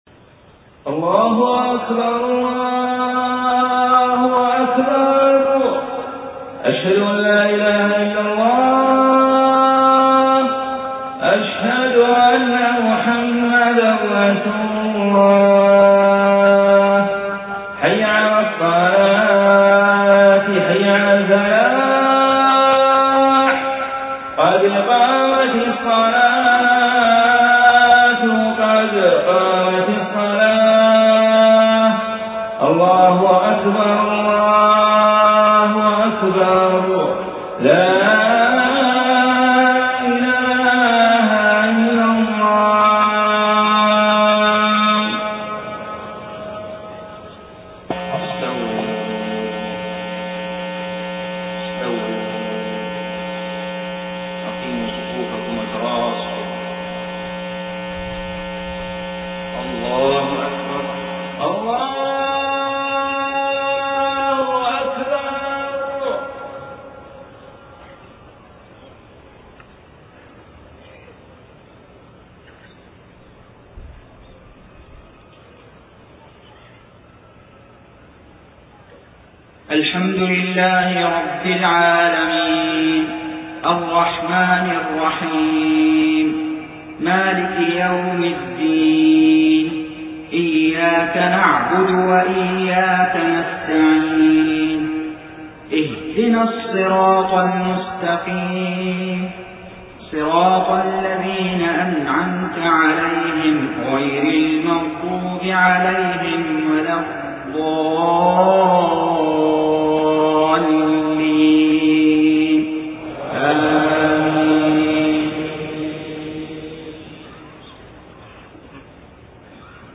صلاة الفجر 23 صفر 1431هـ فواتح سورة يس 1-32 > 1431 🕋 > الفروض - تلاوات الحرمين